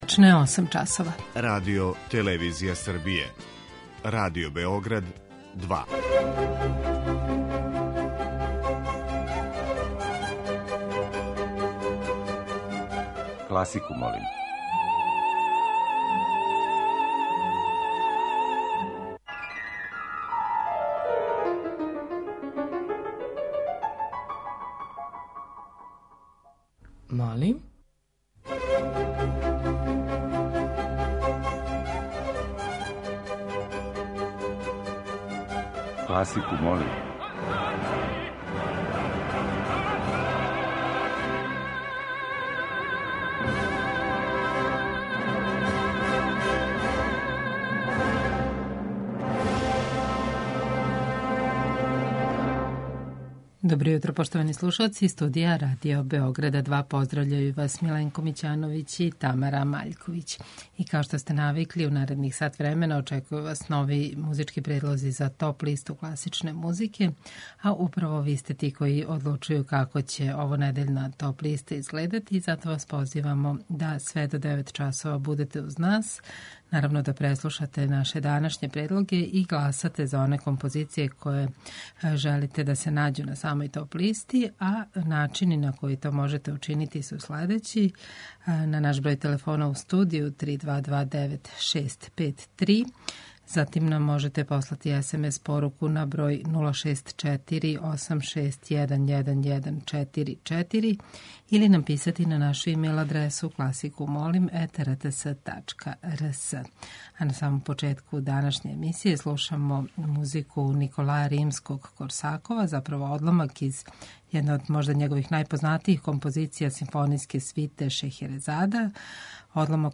У оквиру теме овонедељног циклуса слушаћете одабране одломке из познатих балета.
Уживо вођена емисија, окренута широком кругу љубитеља музике, разноврсног је садржаја, који се огледа у подједнакој заступљености свих музичких стилова, епоха и жанрова. Уредници (истовремено и водитељи) смењују се на недељу дана и од понедељка до четвртка слушаоцима представљају свој избор краћих композиција за које може да се гласа телефоном, поруком или у групи на Фејсбуку.